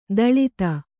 ♪ daḷita